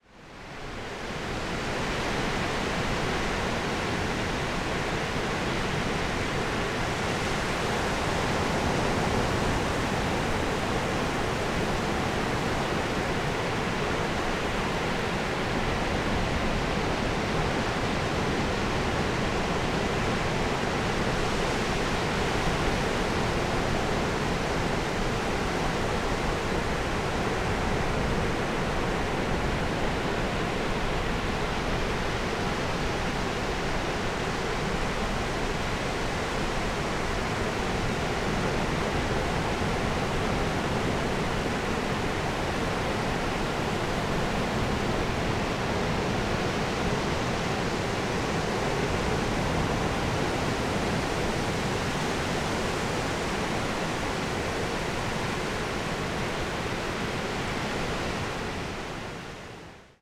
parce que c’est très difficile d’enregistrer (et de restituer) le bruit de l’océan capté d’un peu loin, 50 ou 100 mètres, j’étais au bord d’une baïne à marée basse. À l’écoute on se dit qu’on aurait pu faire aussi bien, voire plus évocateur avec le bruit blanc du synthétiseur ou de Pure Data ;
Plage de la Bouverie
La Tremblade, 2/08/2024, vers 8h30